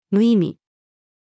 無意味-female.mp3